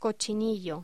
Locución: Cochinillo
voz